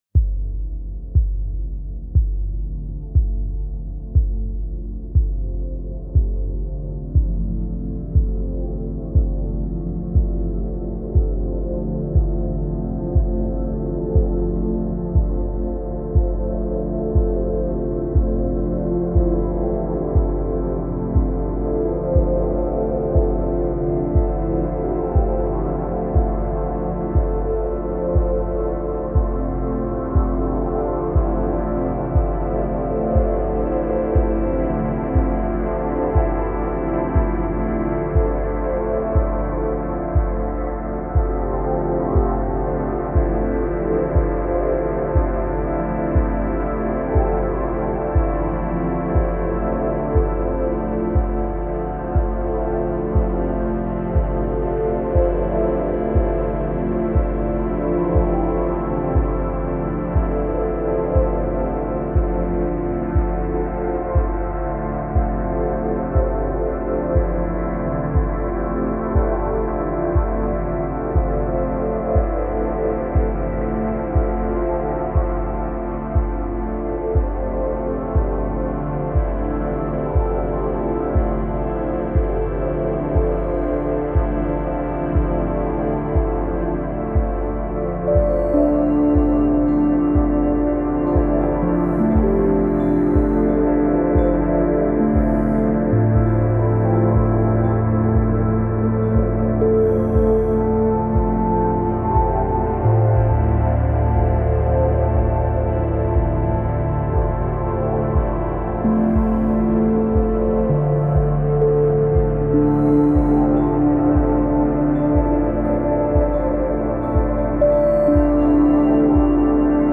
آرامش بخش